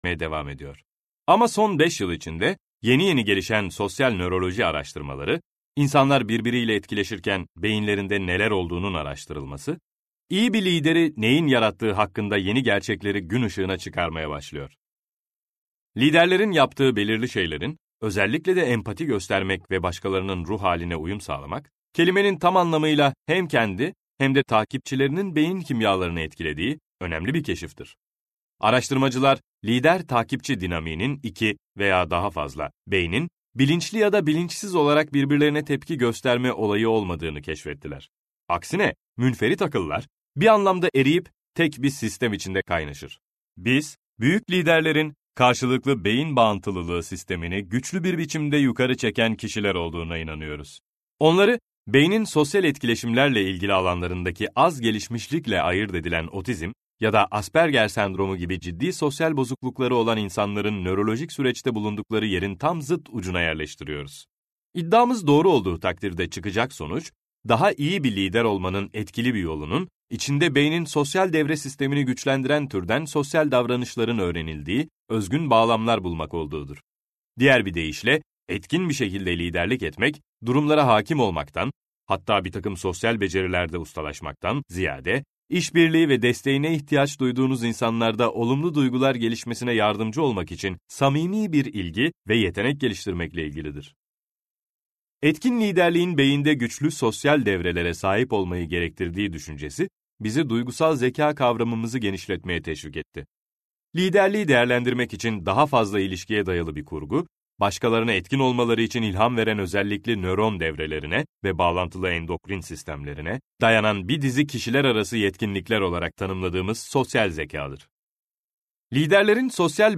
Dinleyeceğiniz makale HBR’s 10 Must Reads serisinde ”İşbirliği” başlıklı kitapta yer alır ve Harvard Business Review dergisinde ilk olarak Eylül 2008’de yayınlanmıştır.